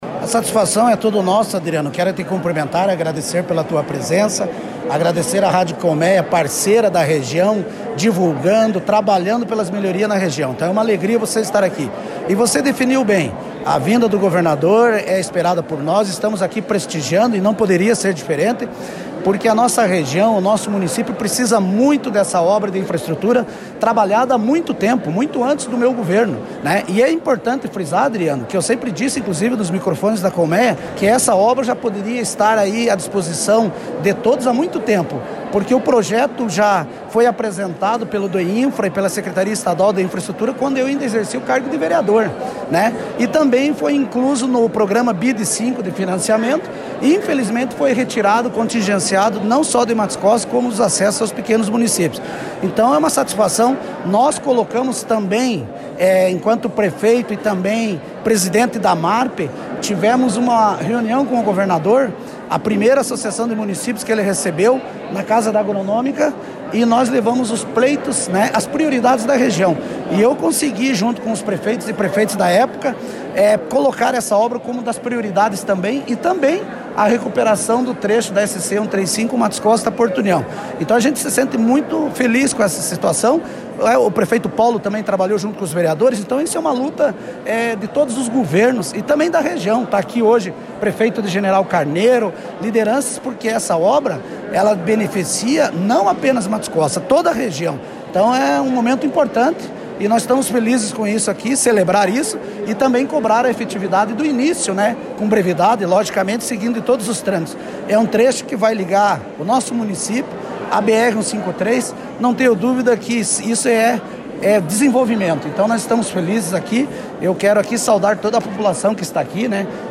O ex-prefeito de Matos Costa, Raul Ribas Neto, que se fez presente no evento falou sobre a importância dos investimentos destinados e também do trabalho realizado anteriormente para tentar trazê-los à região.
EX-PREFEITO-DE-MATOS-COSTA-RAUL-RIBAS-NETO.mp3